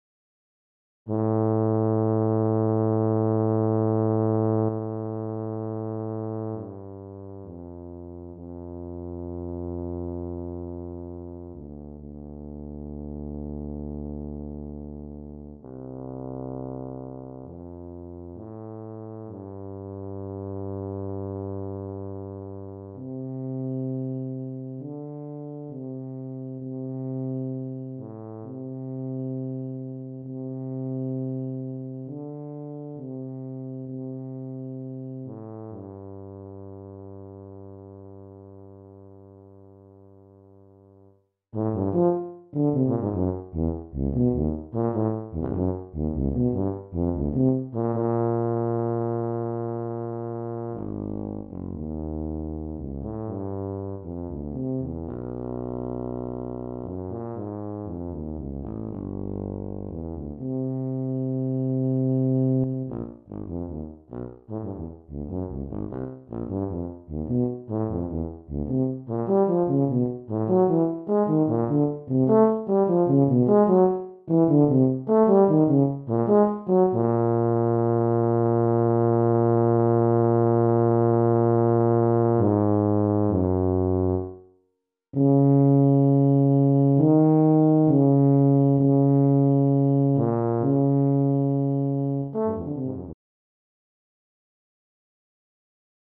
Voicing: Tuba Solo